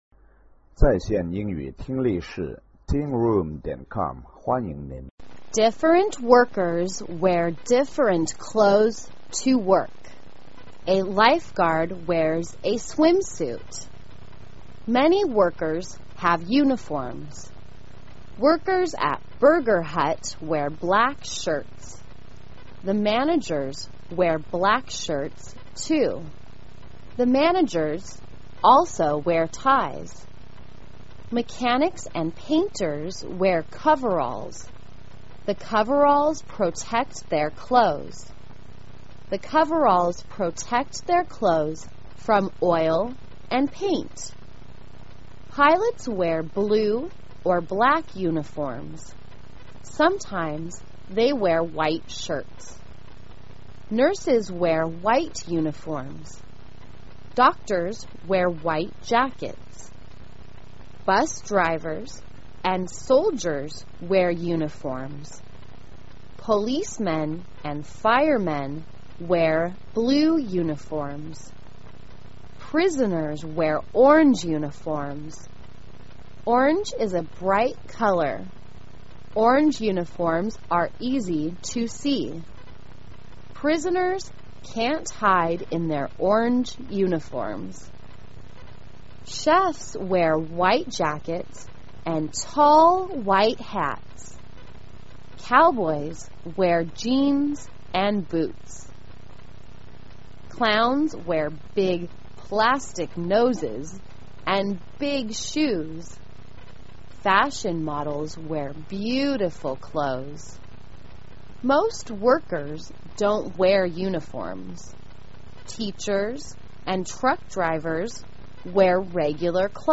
简单慢速英语阅读:What Do You Wear? 听力文件下载—在线英语听力室